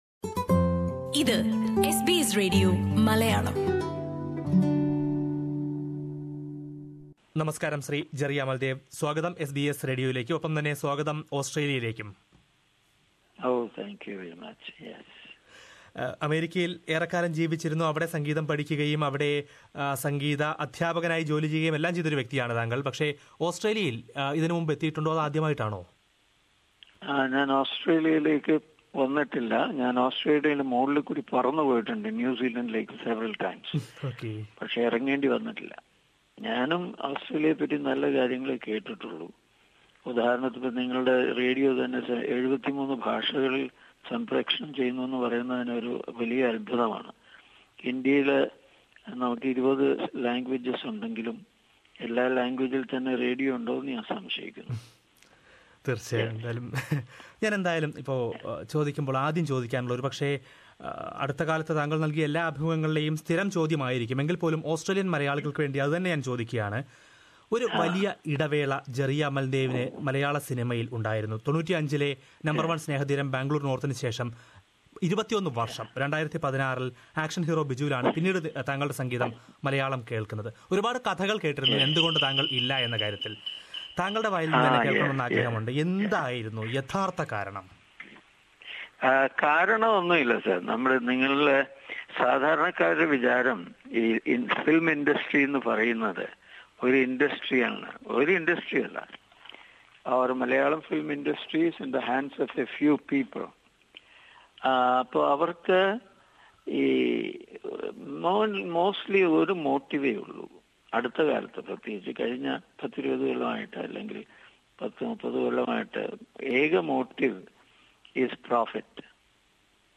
Ahead of his upcoming visit to Australia, famous music director Jerry Amaldev talked to SBS Malayalam Radio.